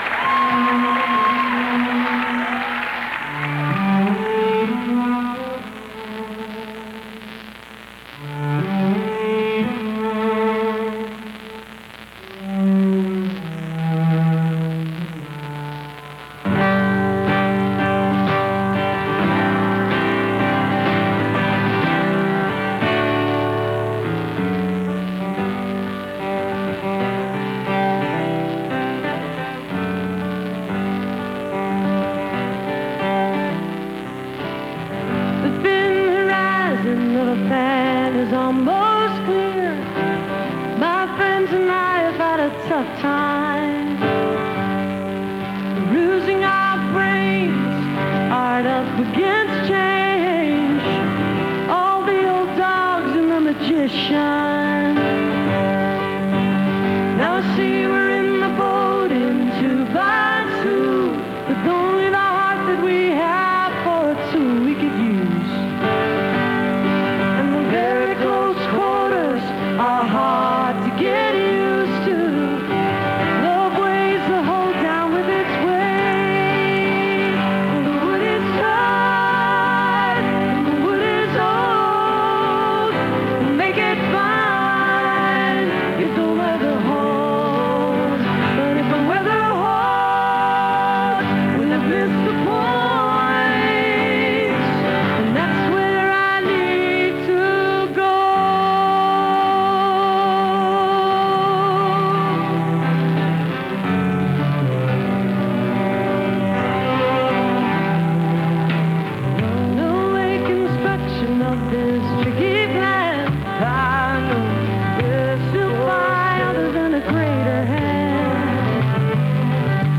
(radio broadcast)